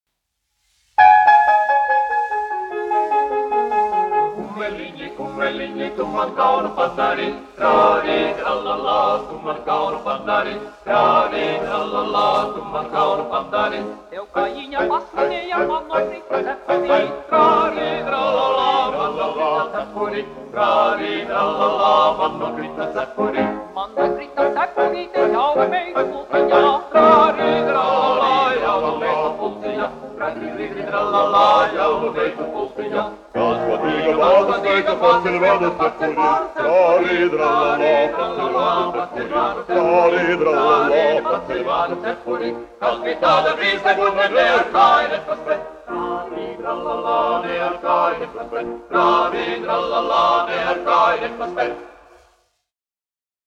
Kumeliņi, kumeliņi : latviešu tautas dziesma
Latvijas Filharmonijas vīru vokālais kvartets, izpildītājs
1 skpl. : analogs, 78 apgr/min, mono ; 25 cm
Latviešu tautasdziesmas
Vokālie kvarteti ar klavierēm